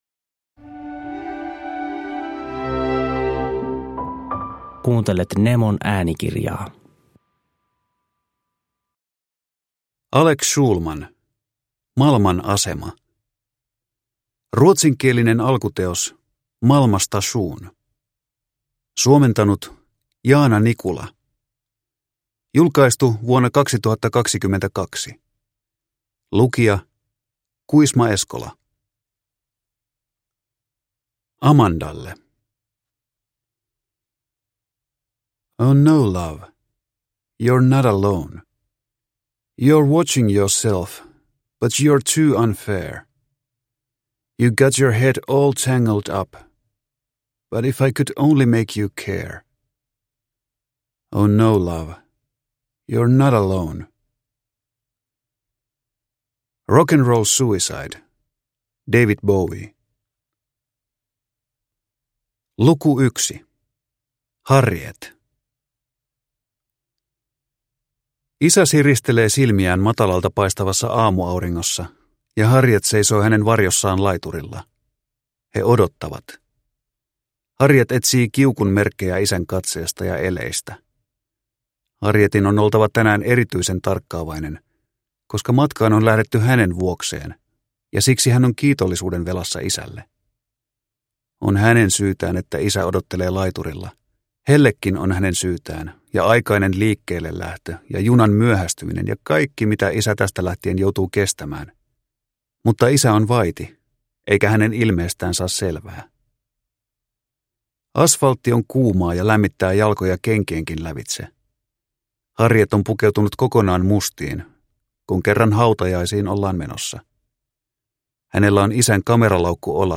Malman asema – Ljudbok – Laddas ner